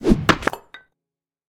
better axe sound.
axe.ogg